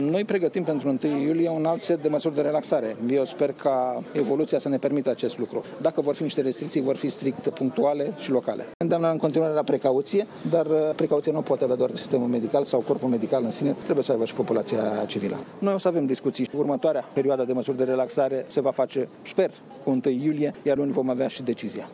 În acest context, ministrul Sănătăţii, Nelu Tătaru, a explicat că, pe fondul măsurilor de relaxare, oamenii NU mai respectă regulile impuse de autorităţi şi pentru că au fost instigaţi să facă acest lucru: